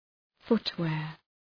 {‘fʋt,weər}
footwear.mp3